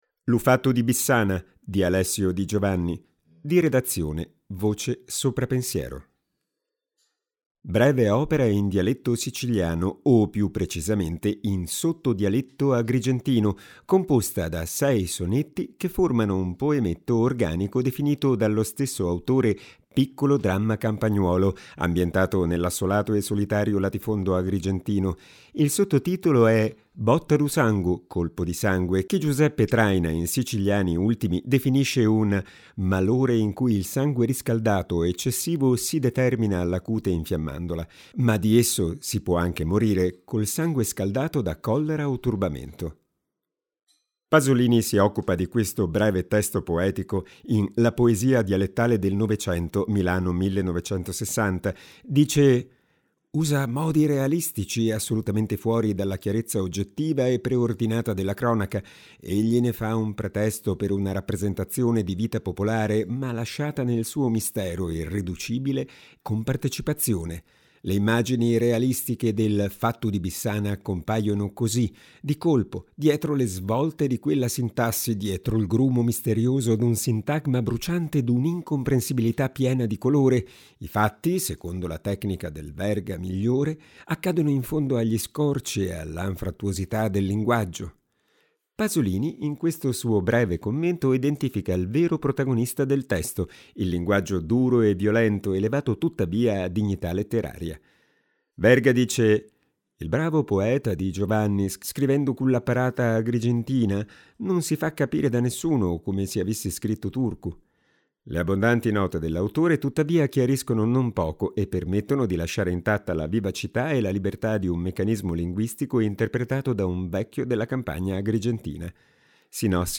Breve opera in dialetto siciliano, o più precisamente in sottodialetto agrigentino, composta da sei sonetti che formano un poemetto organico definito dallo stesso autore «piccolo dramma campagnuolo» ambientato nell’assolato e solitario latifondo agrigentino.